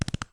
Horse Gallop 2.wav